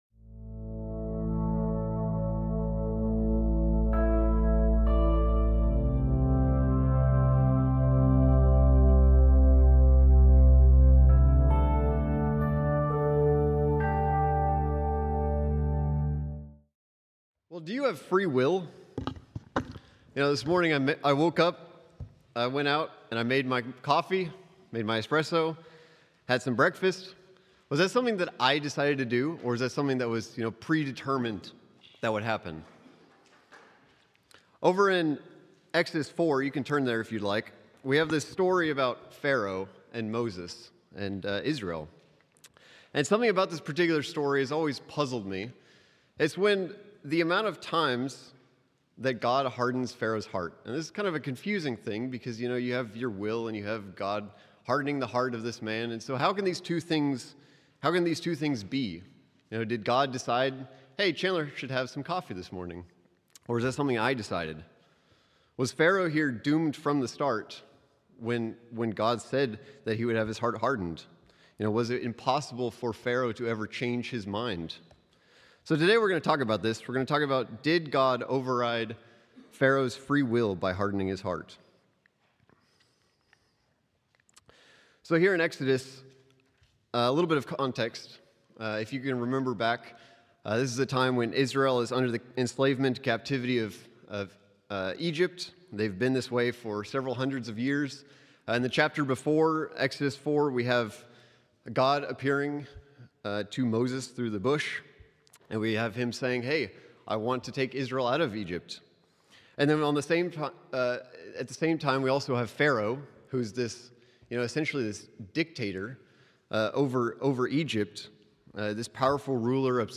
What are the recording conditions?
Given in Oklahoma City, OK Tulsa, OK